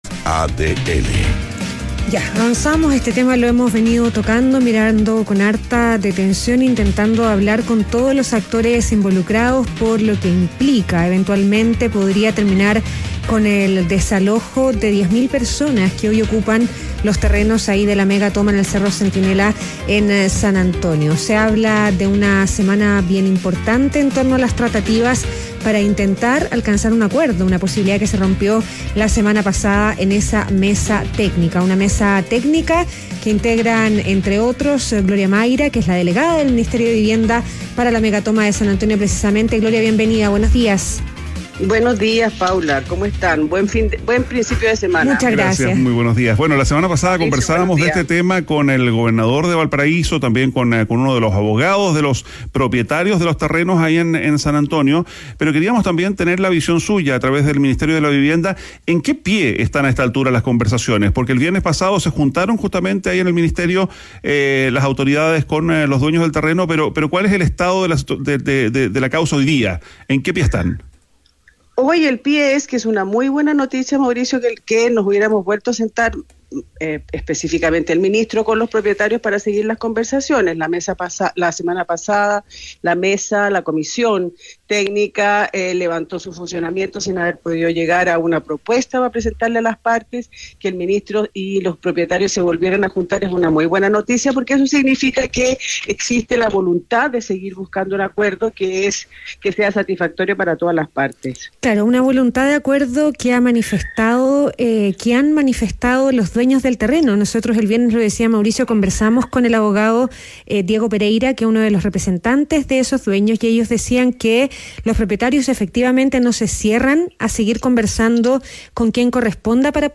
Entrevista a Gloria Maira, Delegada del Ministerio de Vivienda para la megatoma de San Antonio - ADN Hoy